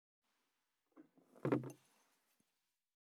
222,机に物を置く,テーブル等に物を置く,食器,グラス,コップ,工具,小物,雑貨,コトン,トン,ゴト,ポン,ガシャン,ドスン,ストン,カチ,タン,バタン,
コップ効果音物を置く